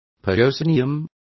Complete with pronunciation of the translation of proscenium.